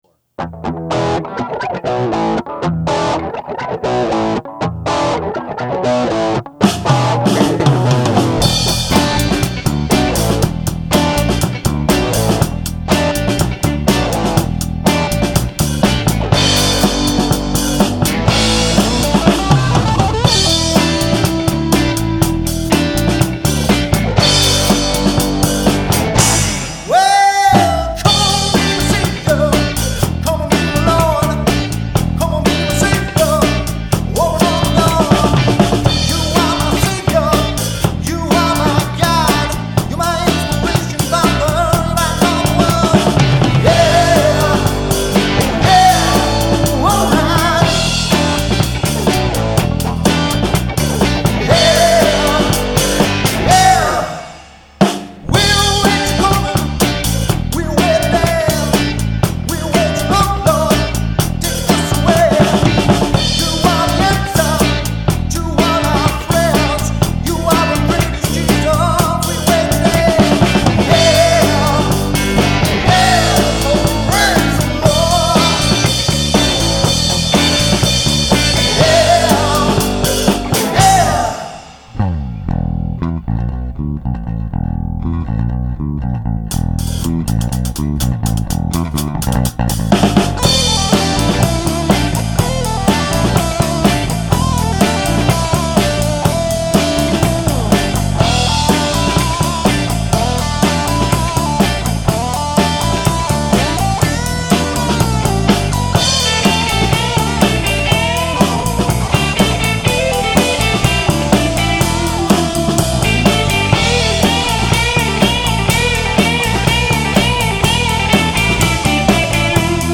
recording and mastering studio
tried hard to keep the 80's alive